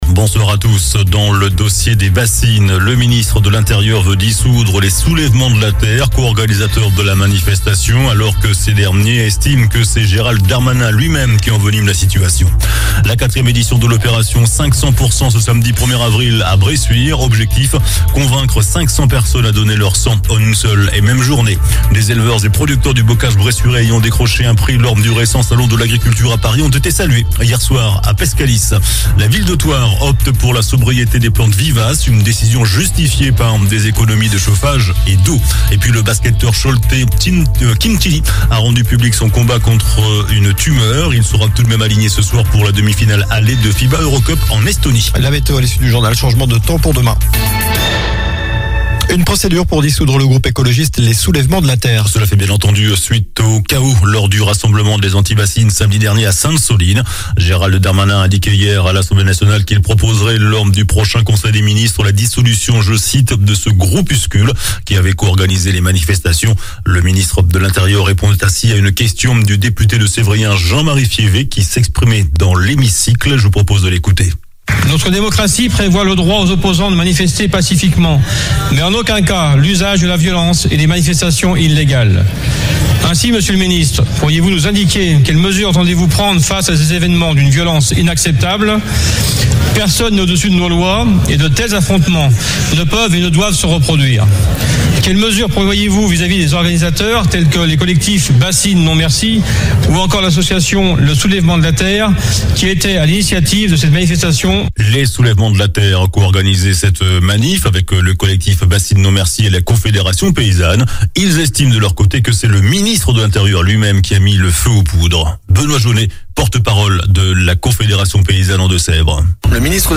JOURNAL DU MERCREDI 29 MARS ( SOIR )